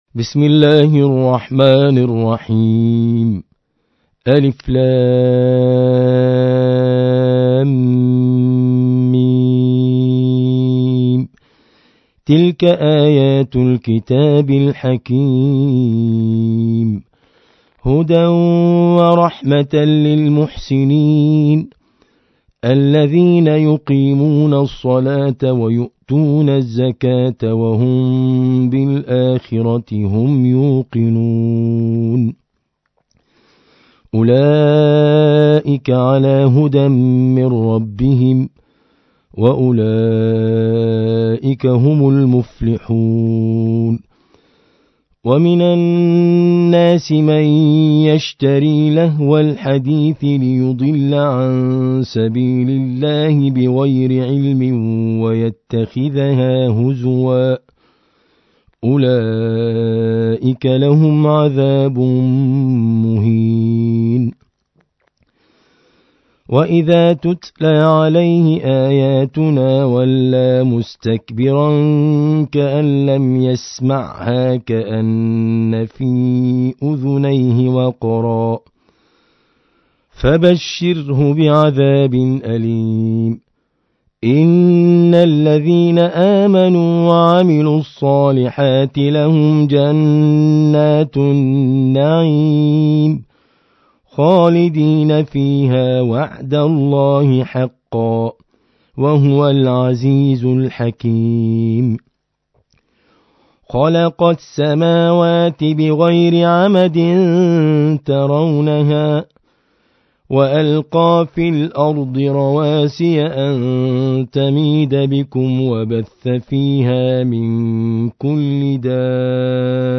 القارئ
القرآن الكريم 31. سورة لقمان